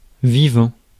Ääntäminen
IPA : /kwɪk/